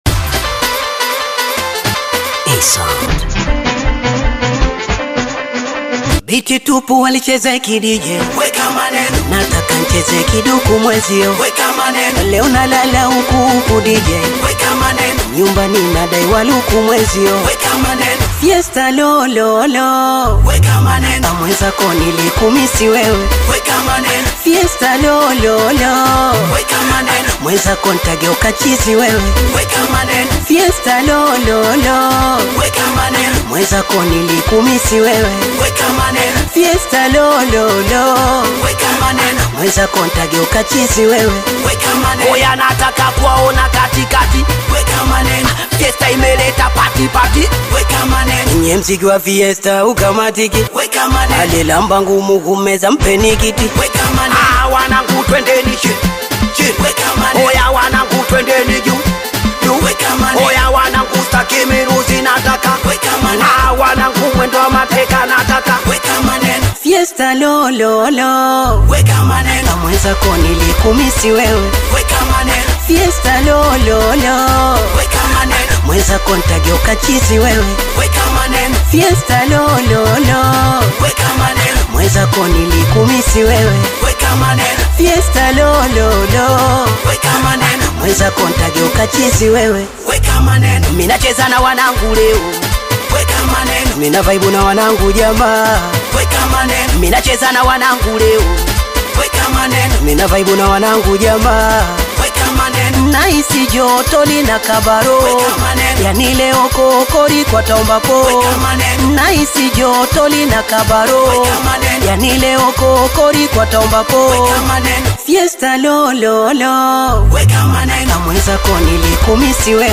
Tanzanian bongo flava, Singeli